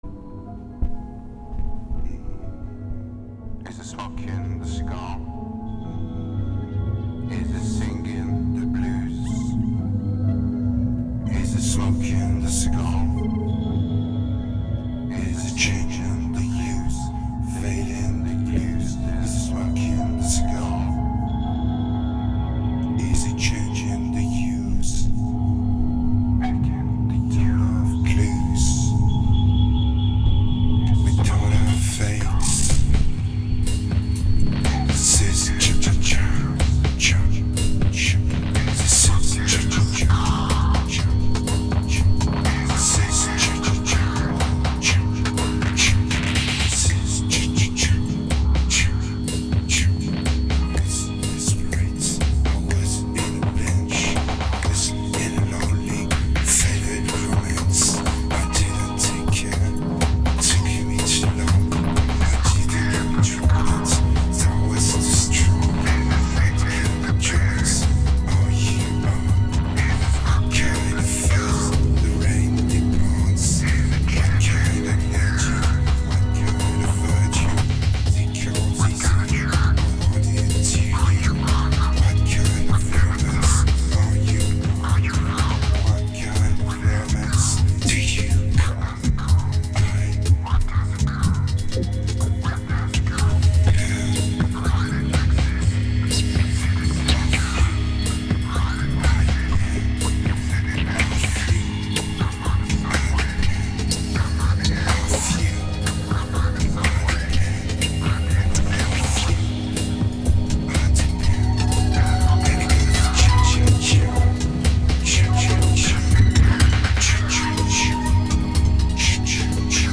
2258📈 - 3%🤔 - 123BPM🔊 - 2009-03-08📅 - -297🌟